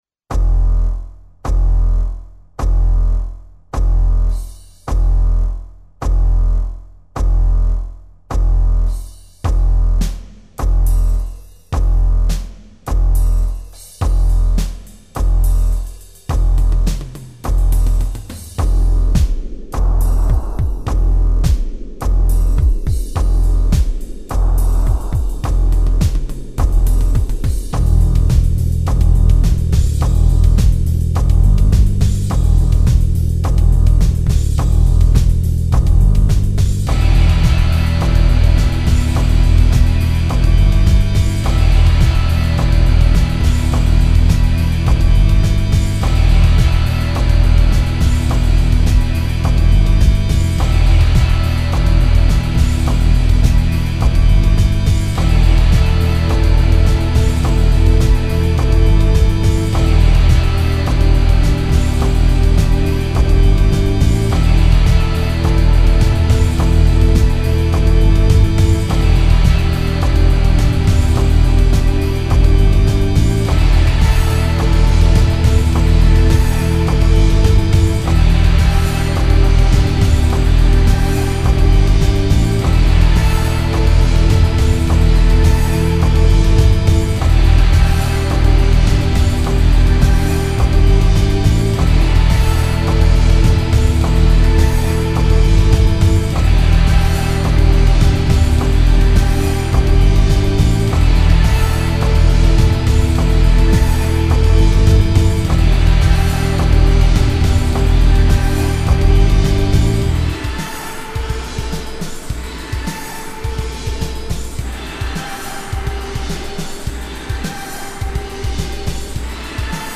Heavy Sub Rock